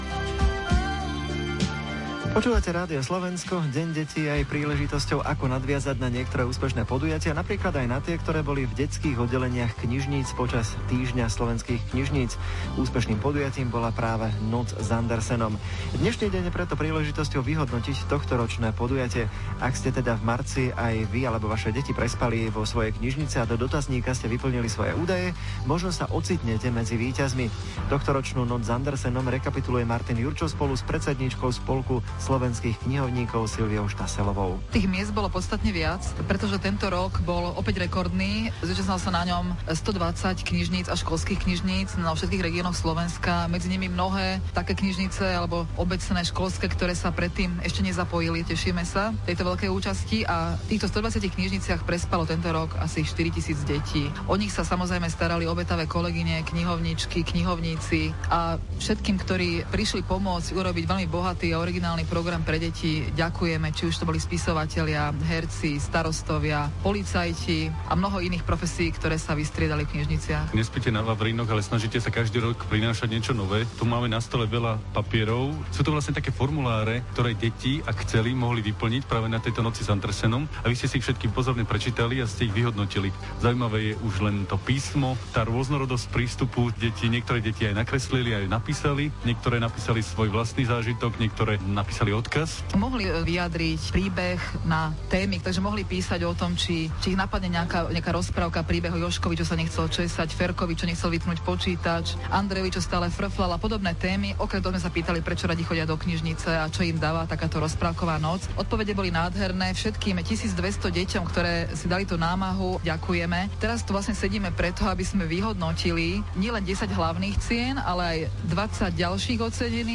Živý vstup v rozhlase